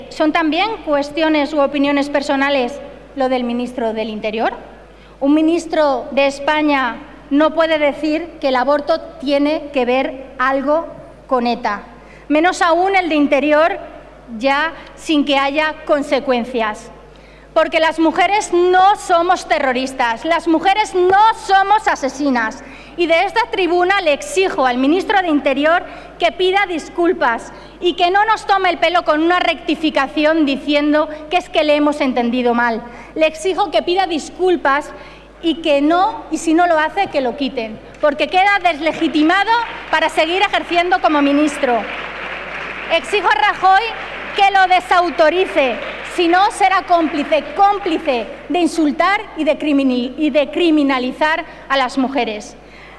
Carmen Monton. Pleno del Congreso 7-5-2013